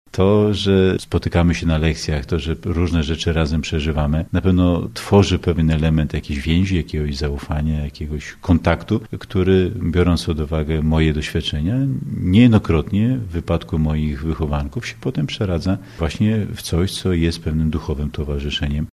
Tak wypowiada się o swoim nauczaniu w szkole.